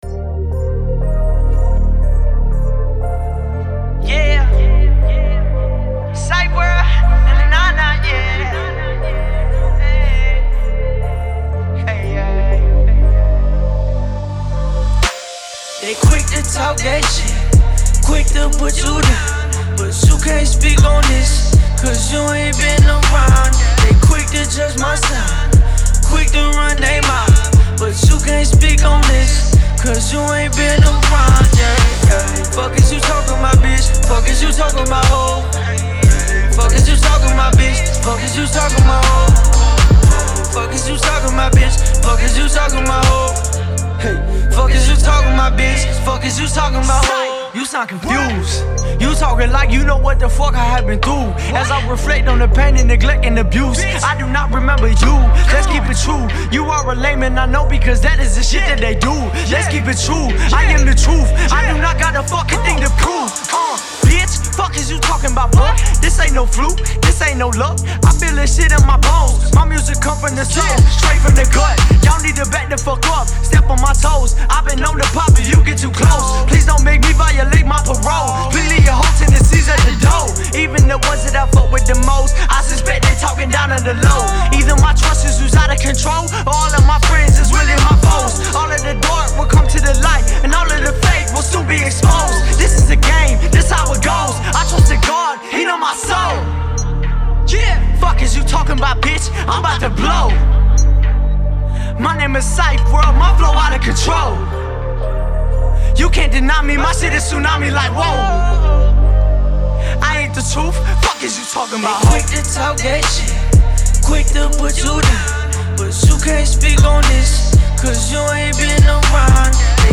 Hiphop
Slow vibe, nice track.